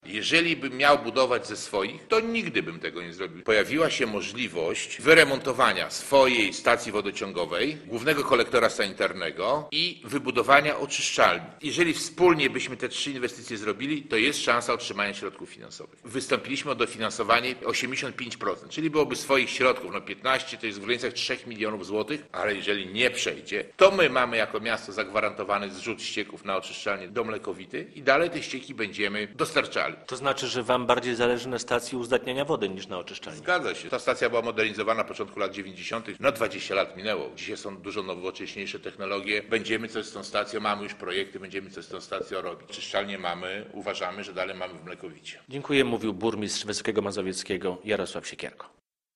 O oczyszczalni z burmistrzem Wysokiego Mazowieckiego Jarosławem Siekierko rozmawia